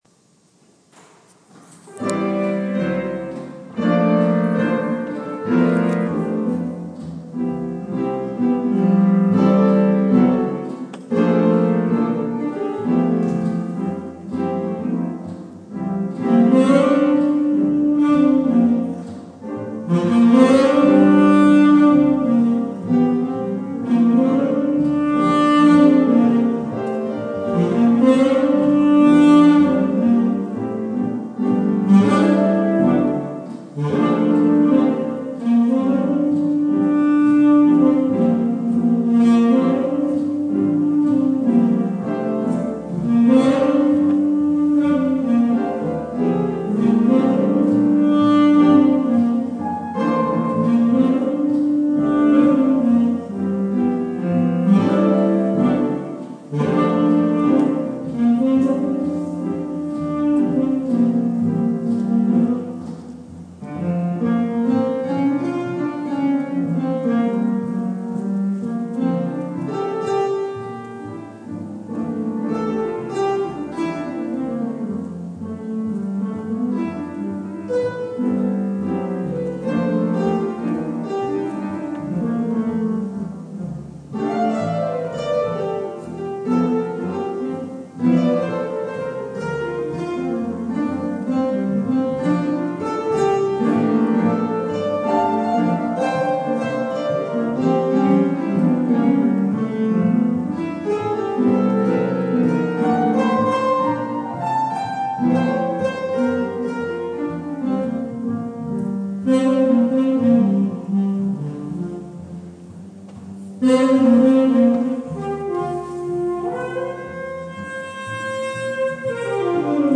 "Vespers & All That Jazz" is housed in the Wightman Chapel, a beautiful church built in the 20s which is listed on the National Historic Register.
A three piece jazz band were positioned at the front of the pews -- one man on grand piano, one man on upright bass and one man who did a variety of woodwinds (sax, flute, oboe).
Interspersed with the readings are songs performed as jazz pieces.
One More Thing: Speaking of pop standards, one of the songs that caught my attention was the only one with lyrics posted in the bulletin (still done instrumental, but you could follow along in your head):
I got so excited about this possibility that I recorded the jazz version of the song on my iPhone which you can listen to it